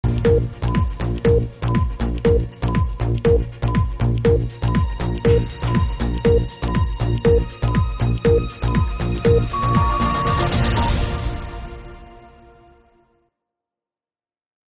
دانلود آهنگ ثانیه شمار 1 از افکت صوتی اشیاء
جلوه های صوتی